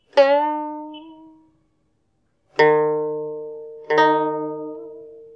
Accent (Finger Press) is pressing strongly on the string to increase tension and hence the pitch.
b- The notes that are available on the fret system of Nguyệt lute: to make the sound more fluid and sweeter than an open string, players do not press on the main fret but pressing on the fret whose pitch is immediately below that of the wanted note, accent upward then pluck.